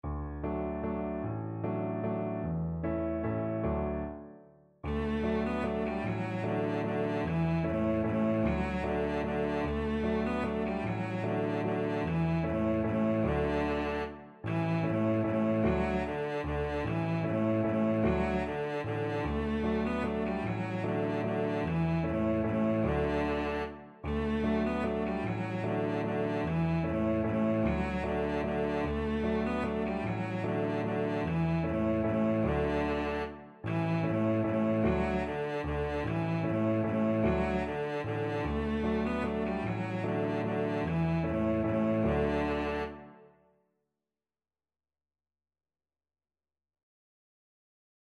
3/4 (View more 3/4 Music)
Steady one in a bar .=c.50
A3-B4
Traditional (View more Traditional Cello Music)